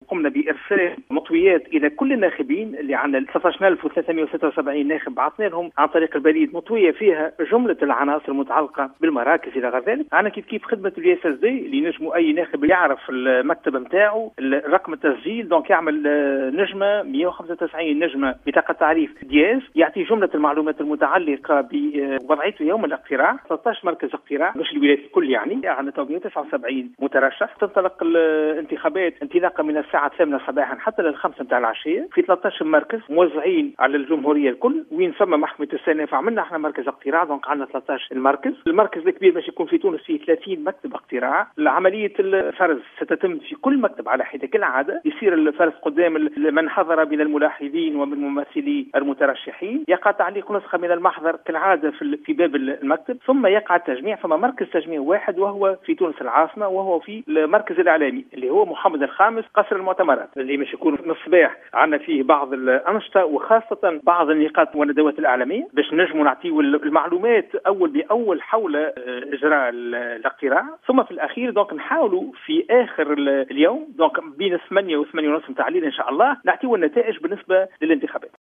وأبرز صرصار، خلال نقطة إعلامية لتسليط الضوء على سير انتخابات المجلس الأعلى للقضاء خلال الفترة الصباحية، عقدت صباح اليوم الأحد، أن نسبة الإقبال بلغت إلى حدود الساعة العاشرة 6.3 %، وقد سجّل أكبر عدد من المقترعين في كلية الحقوق بتونس وبلغ 424 ناخبا، في حين سُجّل أقل عدد في مركز سيدي بوزيد حيث لم يتجاوز عدد من أدلوا بأصواتهم 11 ناخبا إلى غاية الساعة الحادية عشرة.